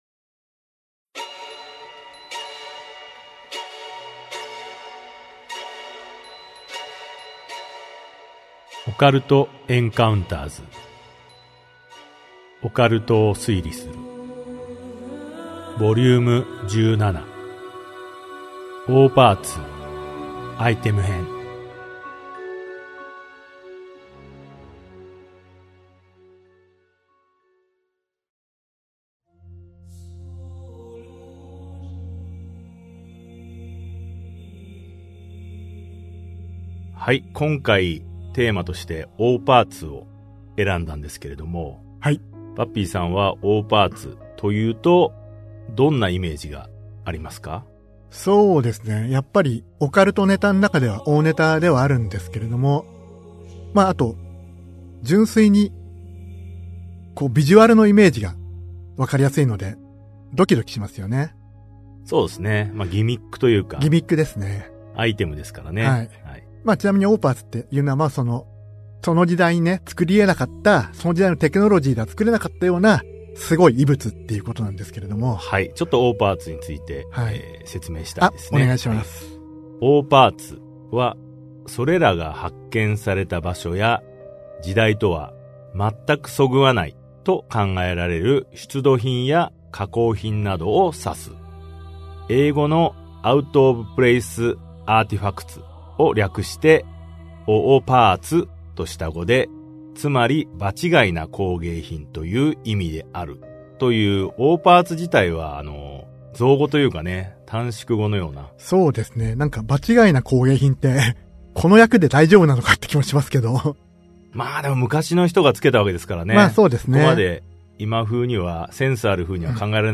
[オーディオブック] オカルト・エンカウンターズ オカルトを推理する Vol.17 オーパーツ編